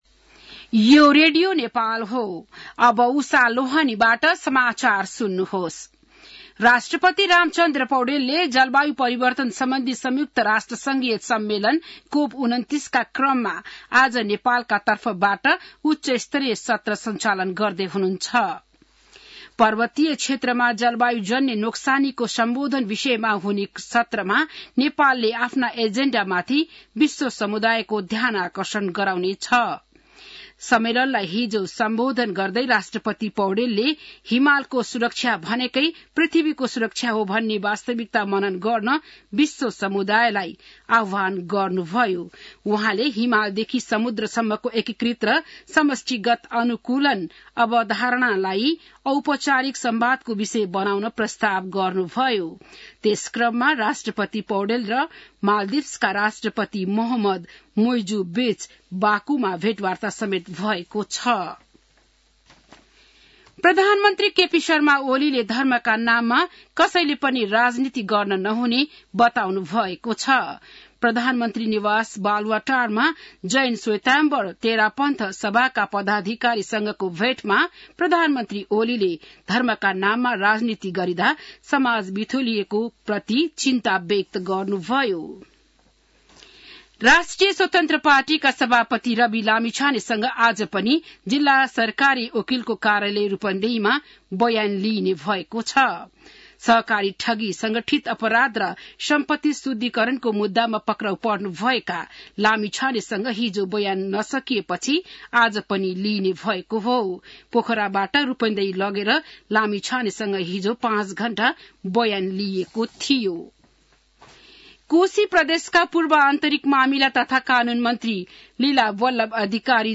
बिहान १० बजेको नेपाली समाचार : २९ कार्तिक , २०८१